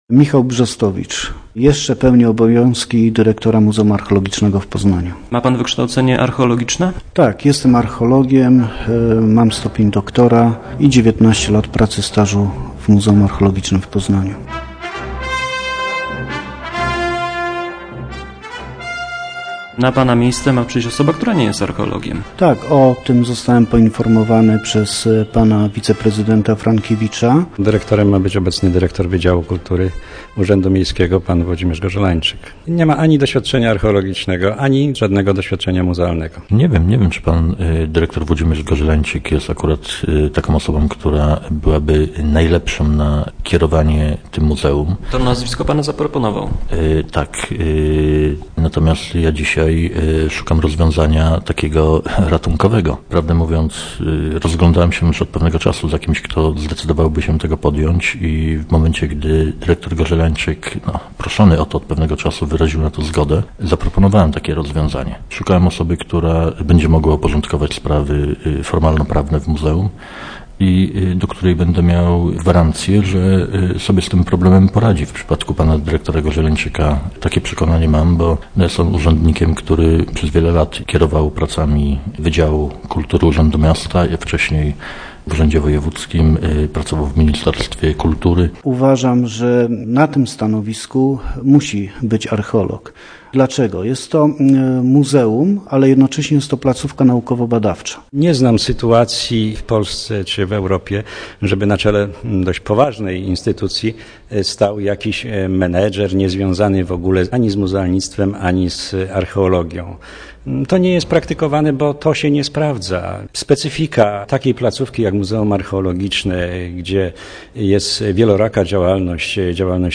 Audycja radiowa "Archeologia Walcz�ca", Pozna�skie Radio EMAUS